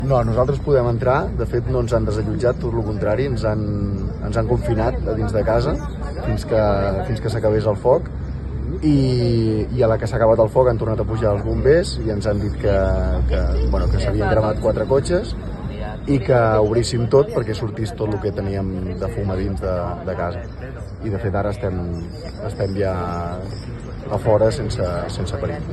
Tot i això, un dels veïns residents ha confirmat que durant l’incendi en cap moment els han fet fora de casa.